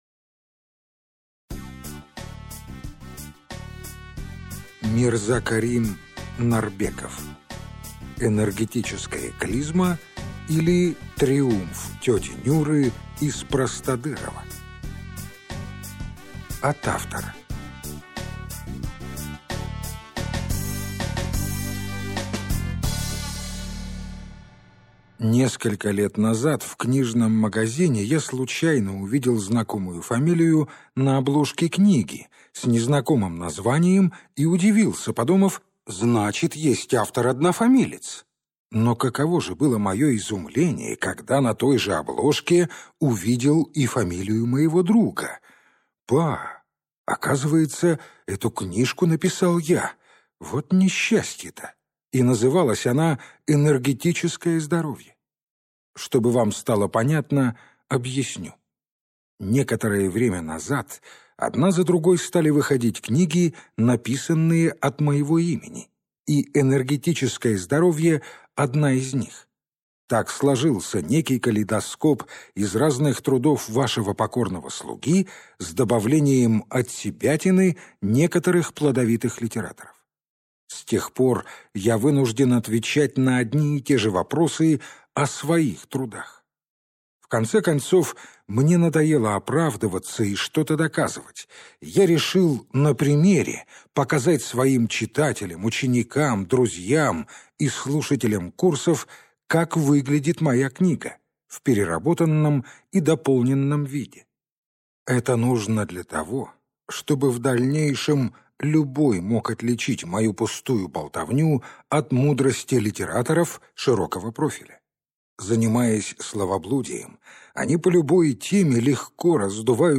Аудиокнига Энергетическая клизма, или Триумф тети Нюры из Простодырово | Библиотека аудиокниг